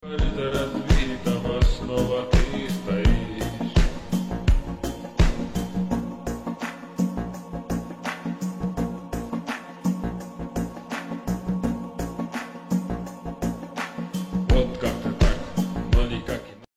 felino domesticado sound effects free download